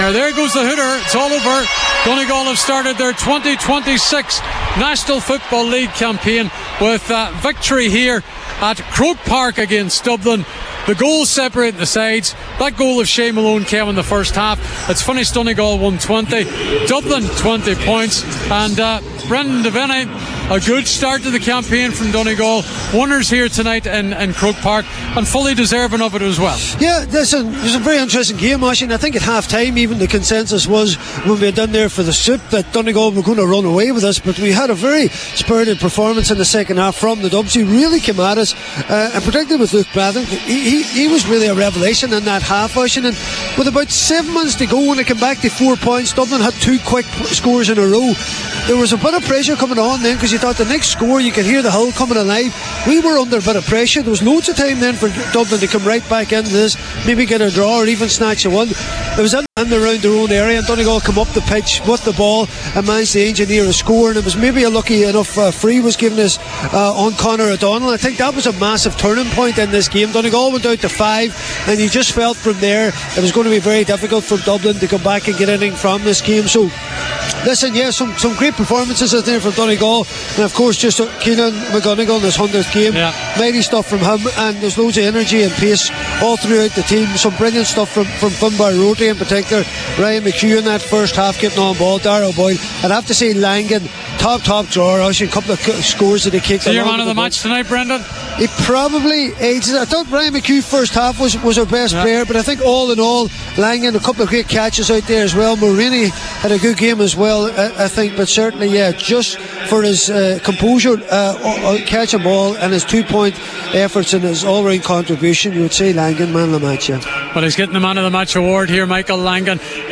live at full time